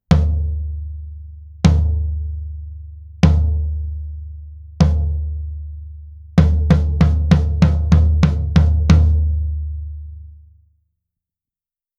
実際の録り音
フロアタム
57ドラムフロア.wav